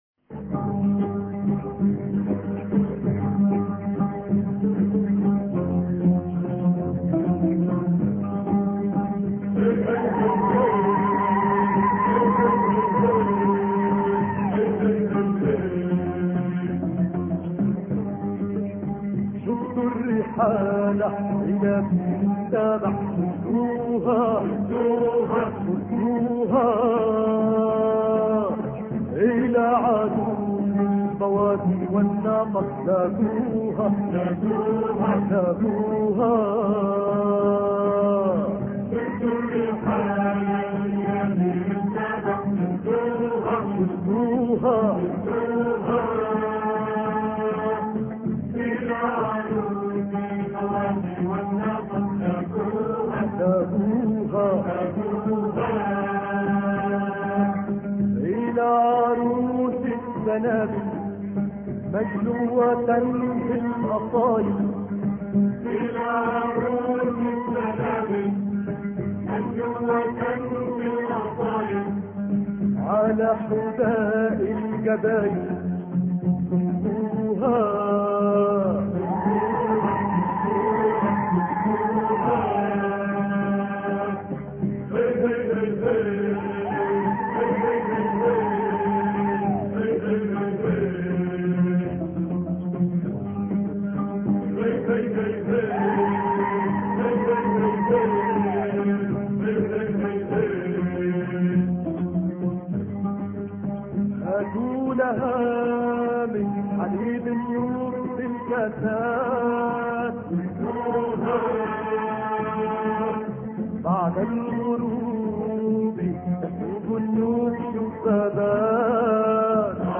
شدوا الرحال الإثنين 21 إبريل 2008 - 00:00 بتوقيت طهران تنزيل الحماسية شاركوا هذا الخبر مع أصدقائكم ذات صلة الاقصى شد الرحلة أيها السائل عني من أنا..